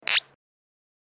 Download Bounce sound effect for free.
Bounce